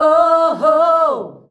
OOOHOO  E.wav